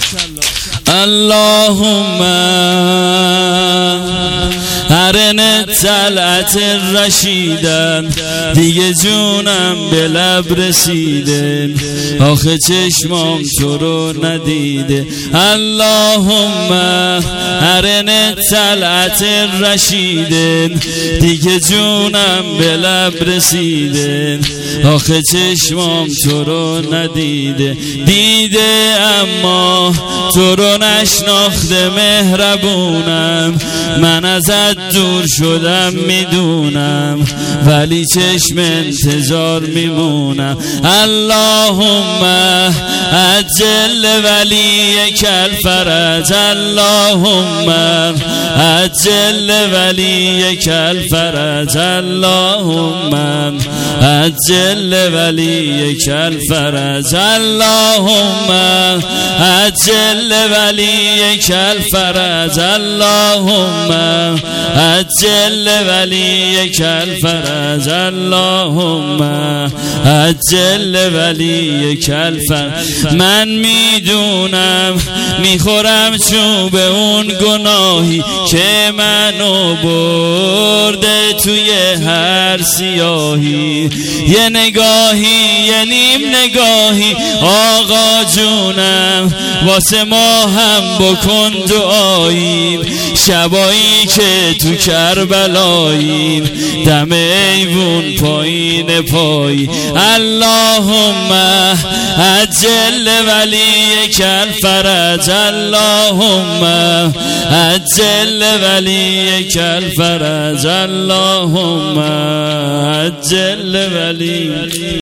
هیئت عاشورا قم